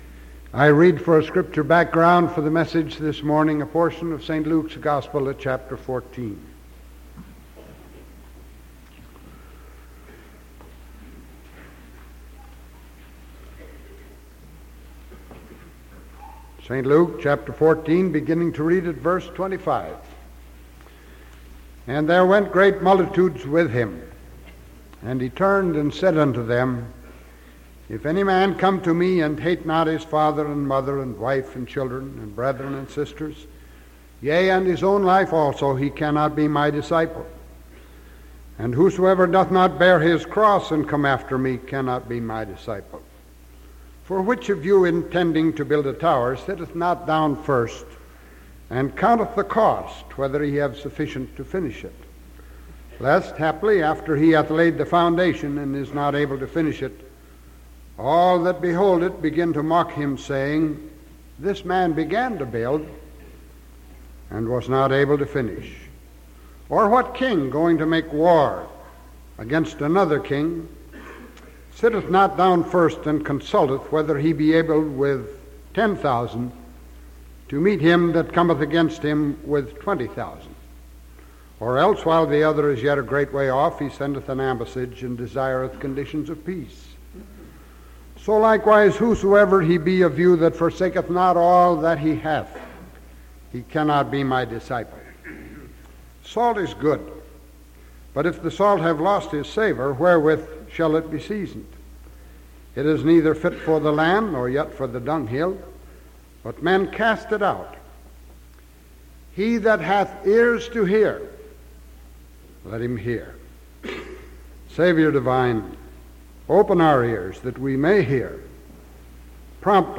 Sermon November 3rd 1974 AM